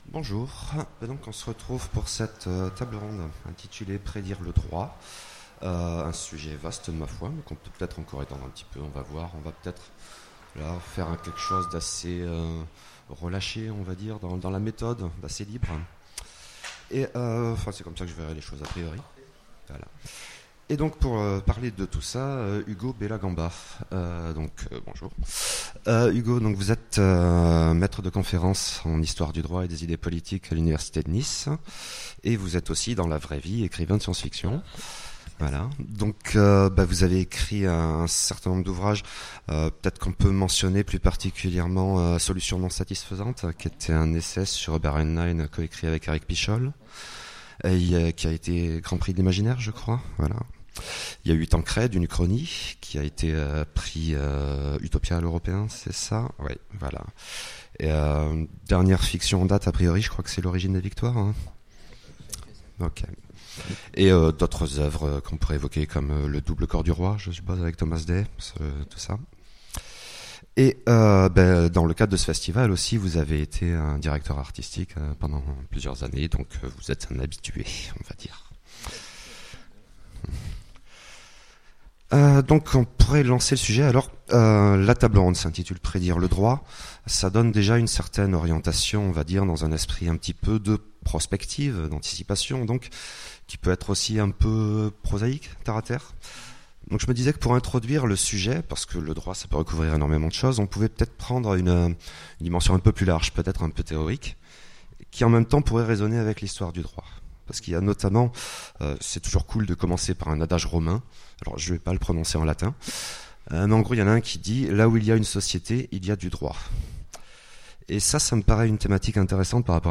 Utopiales 2017 : Conférence Prédire le droit